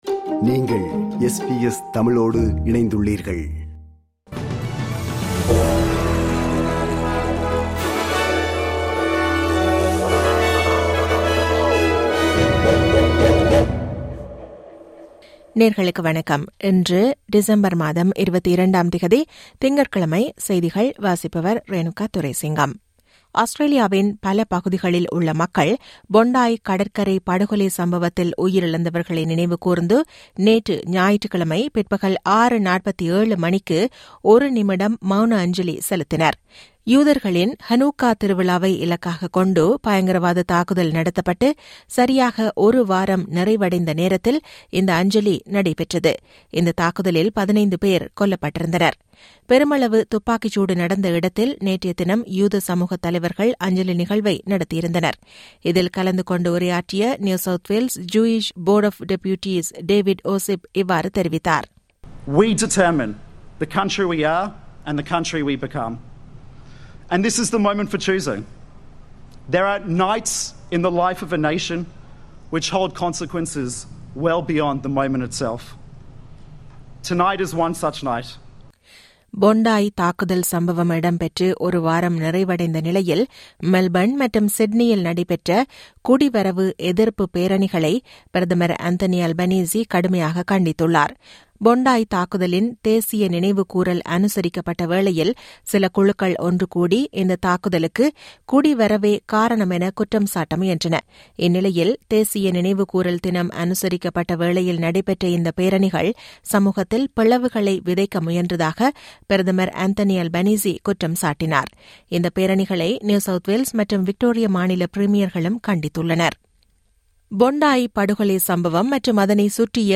இன்றைய செய்திகள்: 22 டிசம்பர் 2025 - திங்கட்கிழமை
SBS தமிழ் ஒலிபரப்பின் இன்றைய (திங்கட்கிழமை 22/12/2025) செய்திகள்.